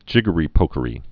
(jĭgə-rē-pōkə-rē)